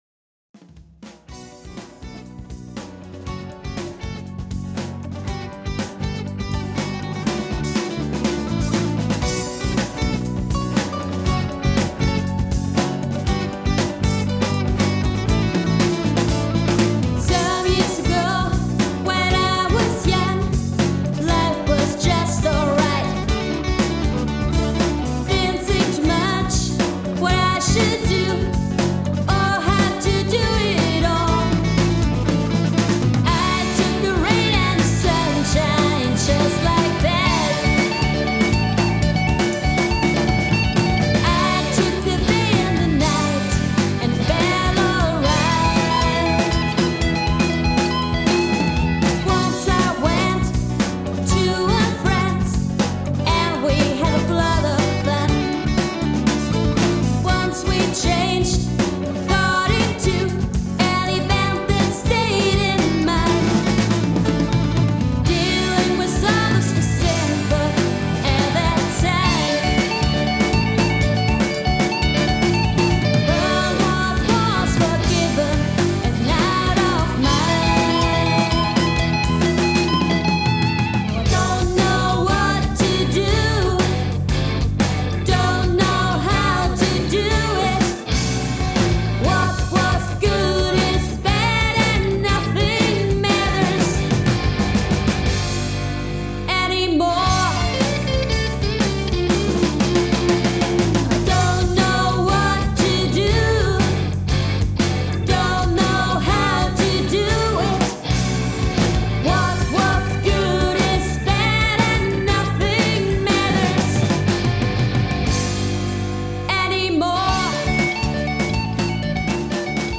Keyboards, Gesang
Gitarren
Bass
Drums